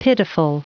Prononciation du mot pitiful en anglais (fichier audio)
Prononciation du mot : pitiful